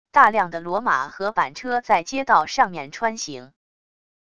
大量的骡马和板车在街道上面穿行wav音频